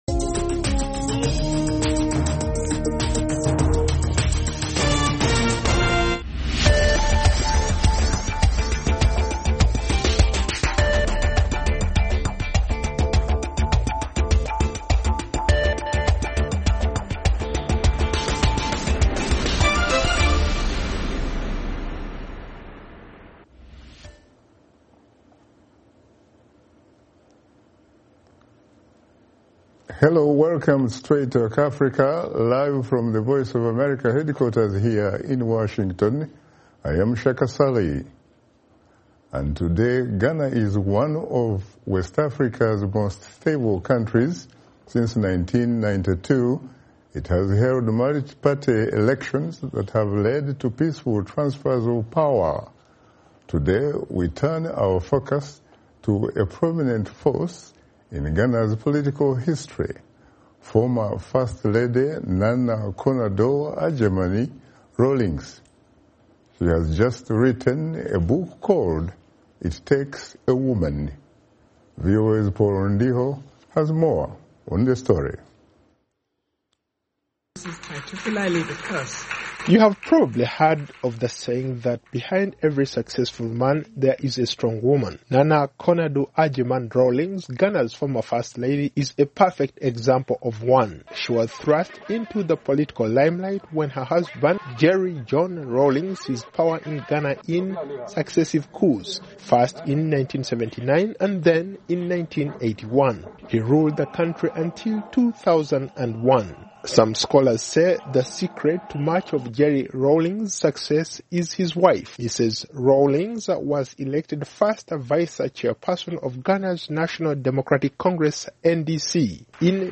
A One on One Interview